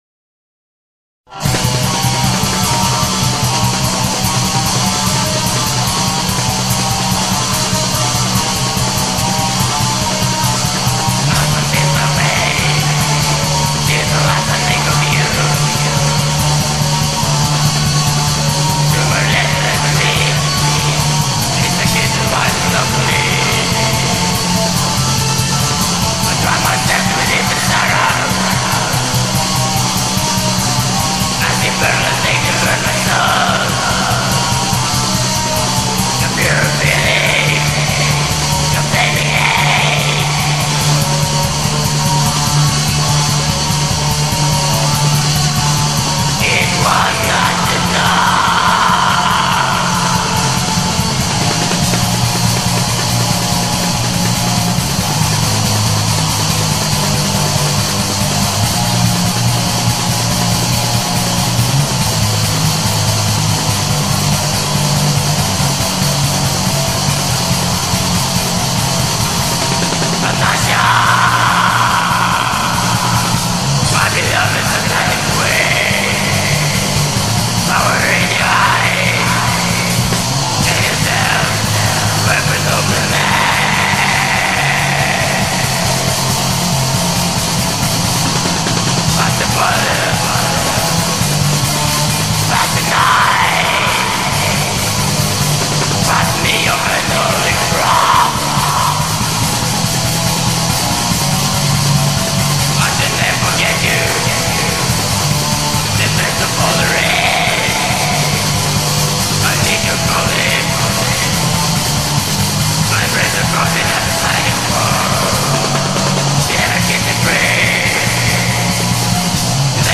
بلک متال